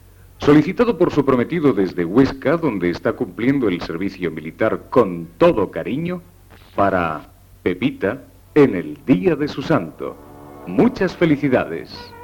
Dedicatòria discogràfica
Musical